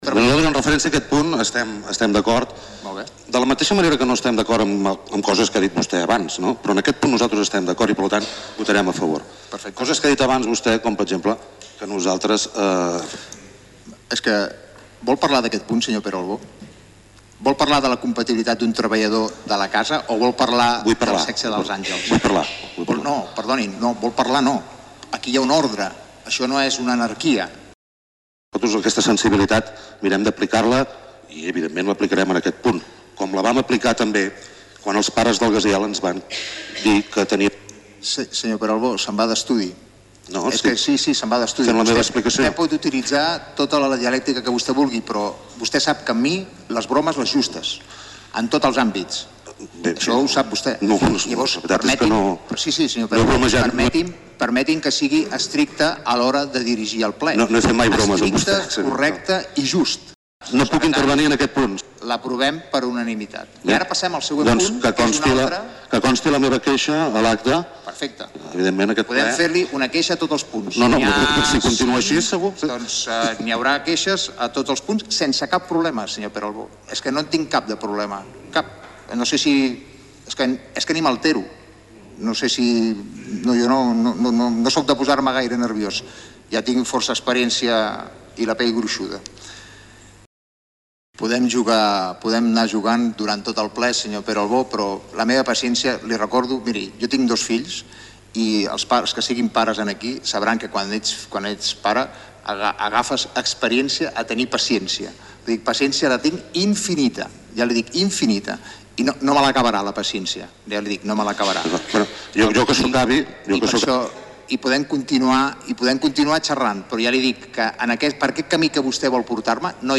La discussió surrealista entre Carles Motas i Pere Albó
L’alcalde Motas ha volgut concloure el punt, però la discussió no ha acabat i s’ha perllongat.
Finalment, com que Albó volia rebatre Motas per la qüestió urbanística, ha anat intentant treure el tema en altres punts, i l’alcalde l’ha anat tallat repetidament.
Discussio-Albo-Motas-altres-punts.mp3